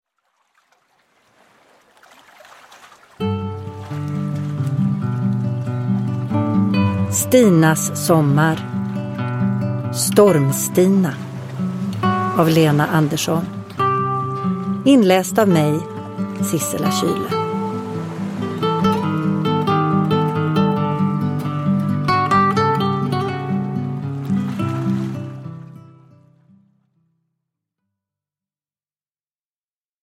Storm-Stina – Ljudbok – Laddas ner
Uppläsare: Sissela Kyle